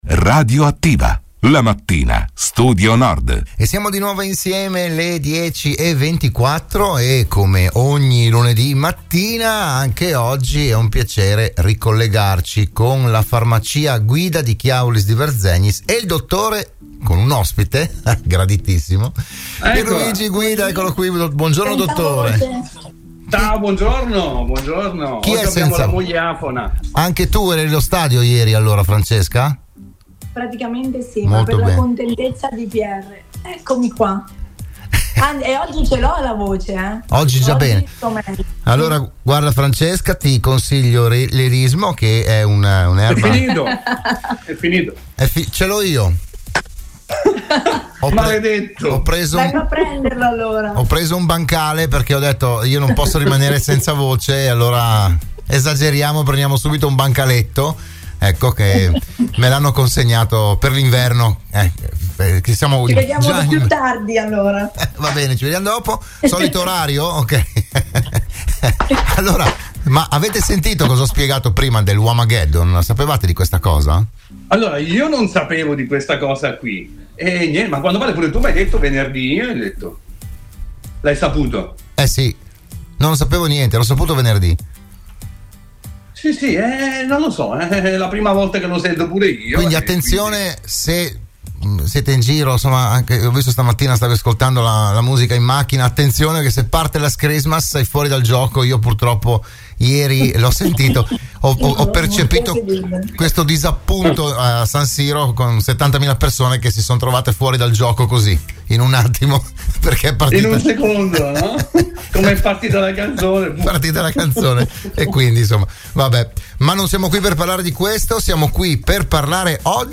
Nuova puntata per “Buongiorno Dottore”, il programma di prevenzione e medicina in onda ogni lunedì mattina all’interno della trasmissione di Radio Studio Nord “RadioAttiva”.